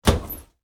household
Medicine Cabinet Door Open